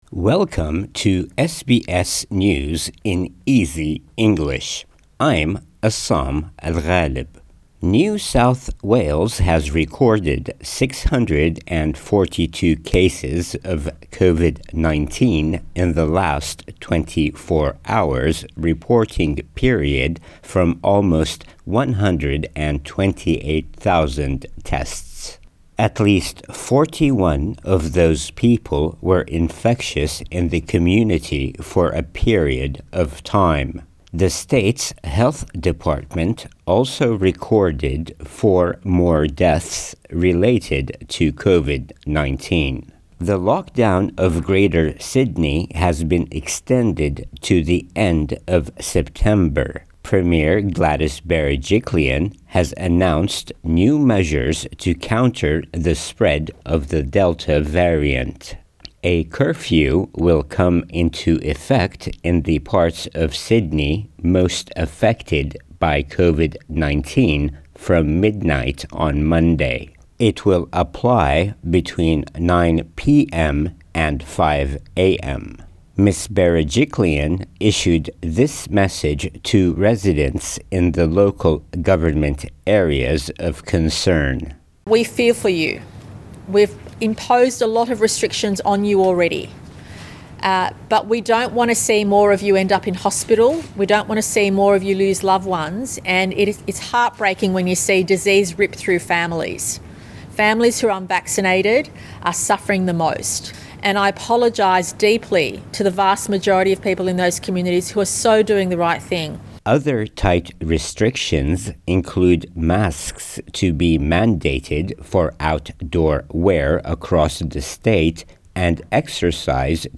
A daily 5 minutes news wrap for English learners.